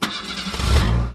gas.ogg